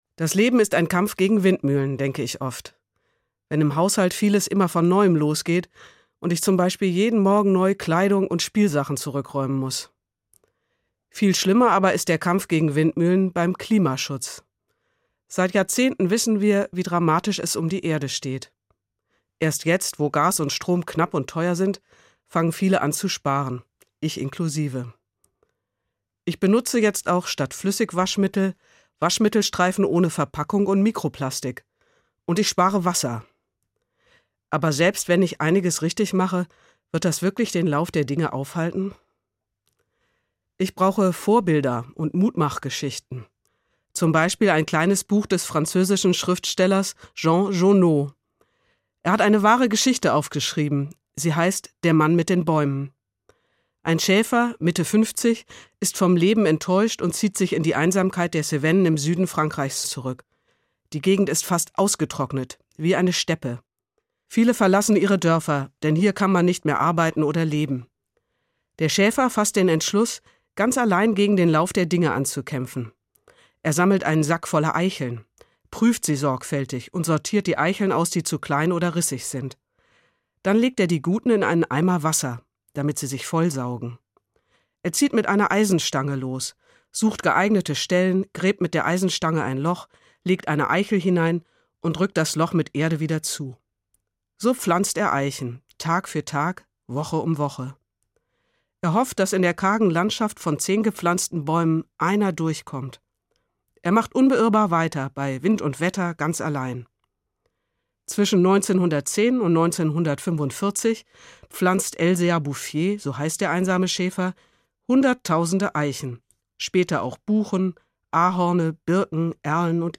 Evangelische Pfarrerin, Frankfurt